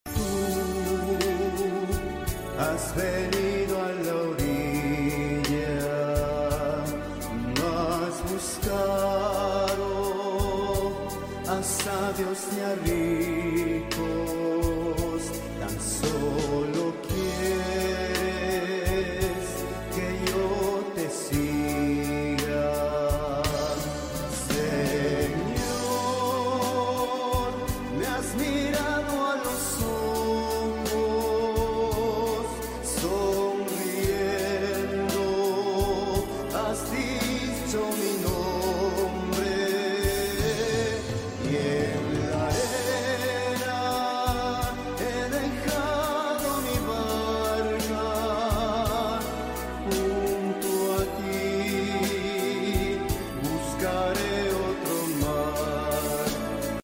Voici une version magnifiquement chantée